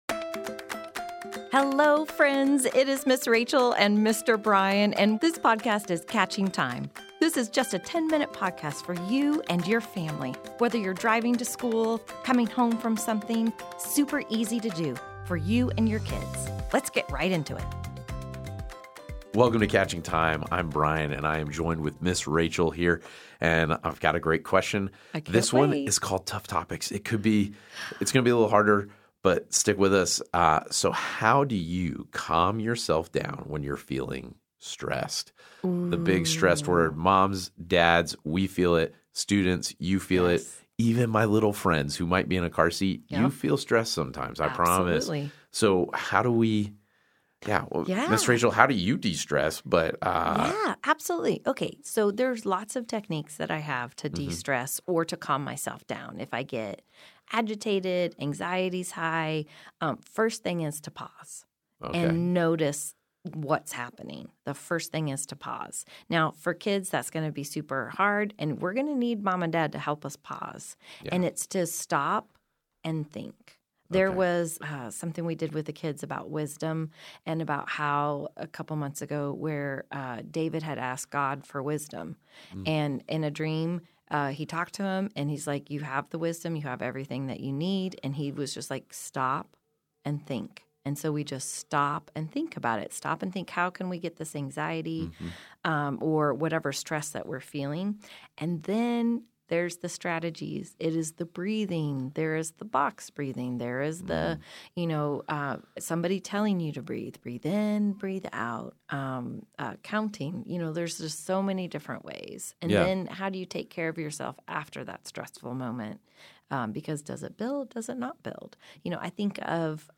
A message from the series "2024 - 2025."